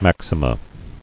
[max·i·ma]